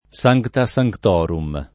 vai all'elenco alfabetico delle voci ingrandisci il carattere 100% rimpicciolisci il carattere stampa invia tramite posta elettronica codividi su Facebook sancta sanctorum [lat. S#j kta S a j kt 0 rum ] locuz. m. (in it.)